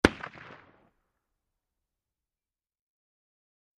Elephant 477 Pistol Shot From Distant Point of View, X3